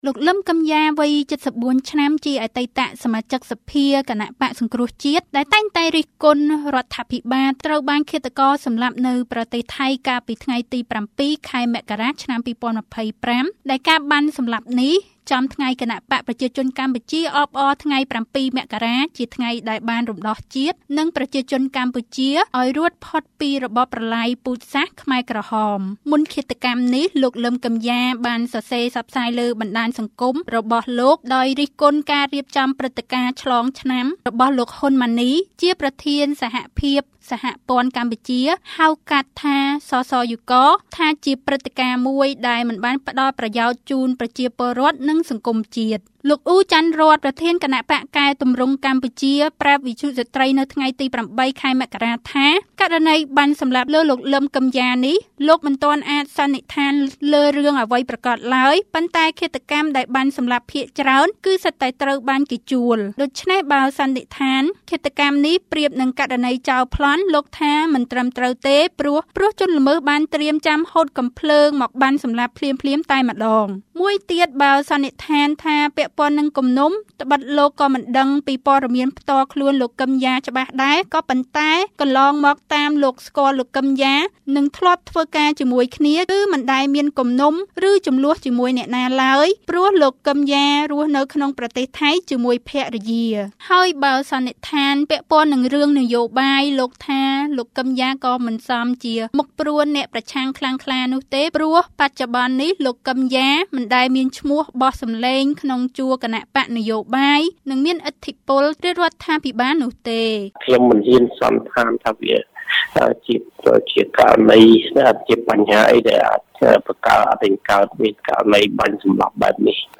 រាយការណ៍